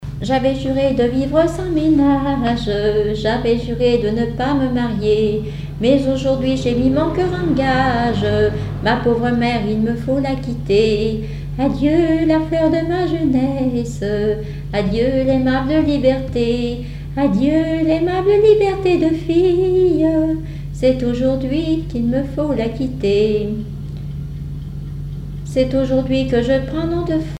Mémoires et Patrimoines vivants - RaddO est une base de données d'archives iconographiques et sonores.
Genre strophique
chansons et témoignages parlés
Pièce musicale inédite